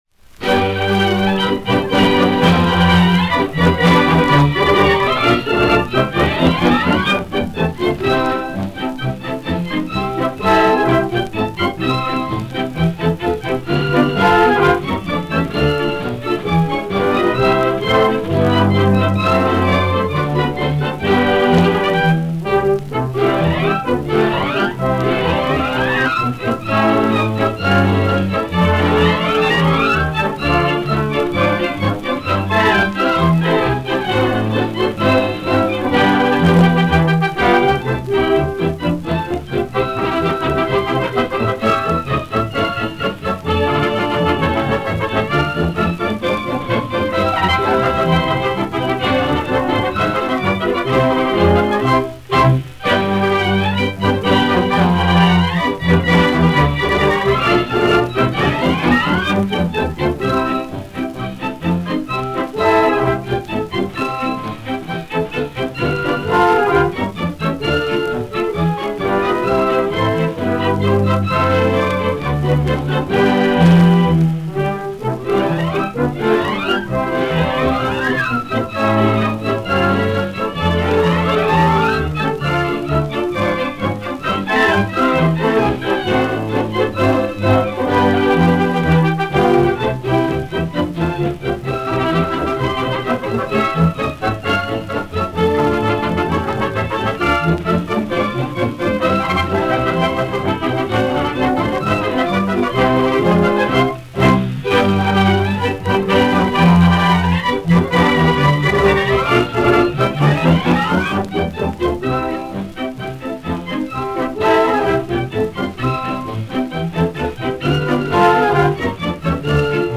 Описание: Повышение качества имеющейся на сайте записи.